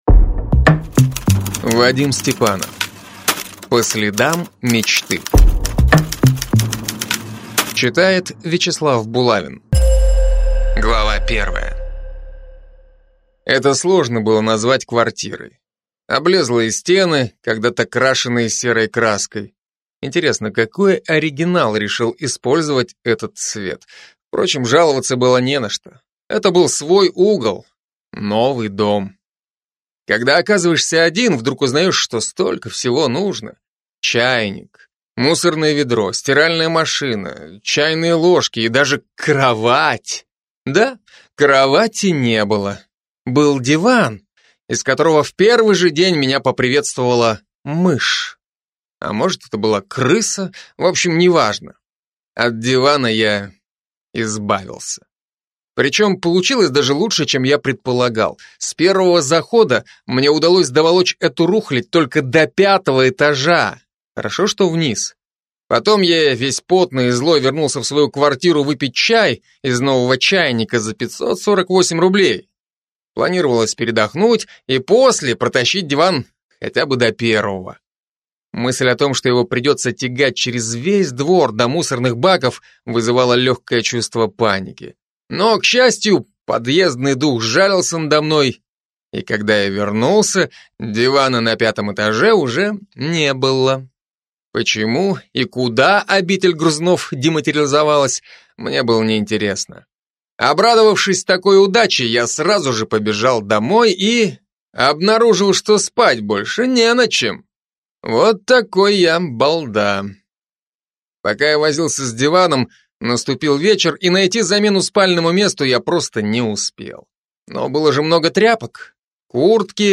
Aудиокнига По следам мечты